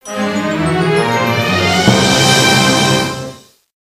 The music for when a player gets a monopoly